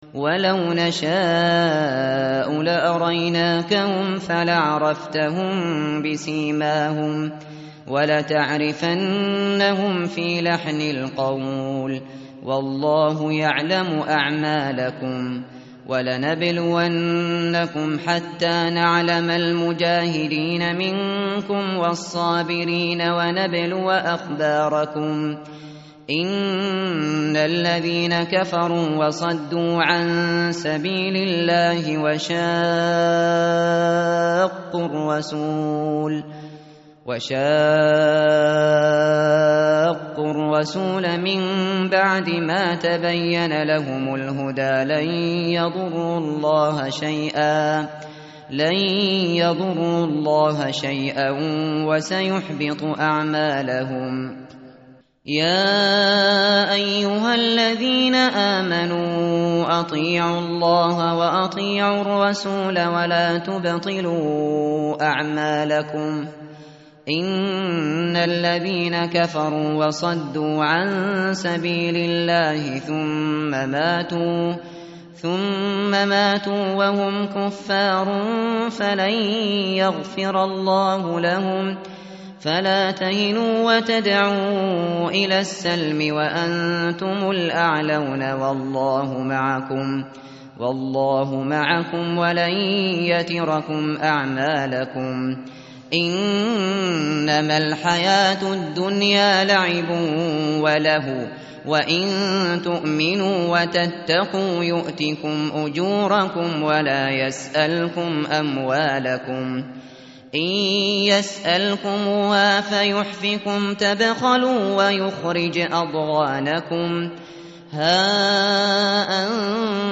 tartil_shateri_page_510.mp3